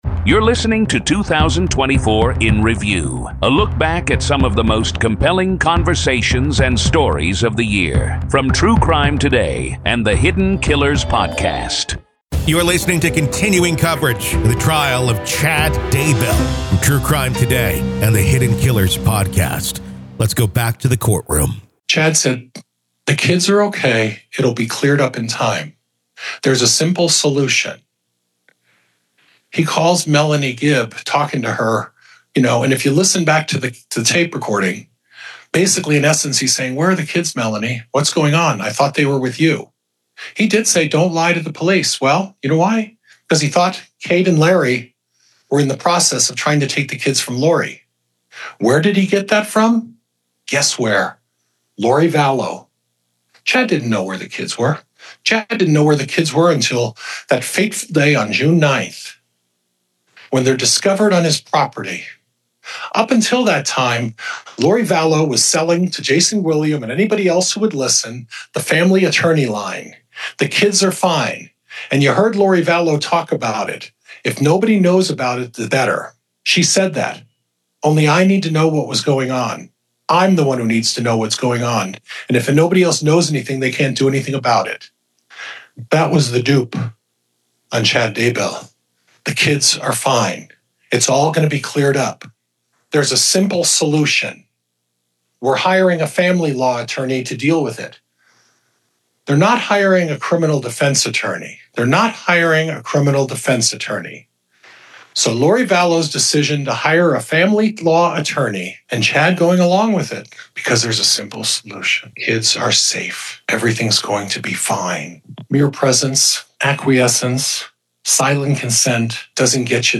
Defense Closing Arguments ID v Chad Daybell, Doomsday Prophet Murder Trial PART 2-2024 Year in Review